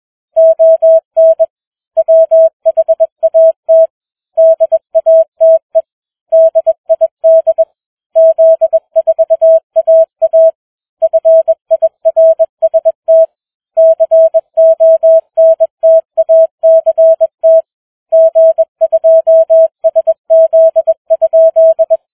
Morse challenge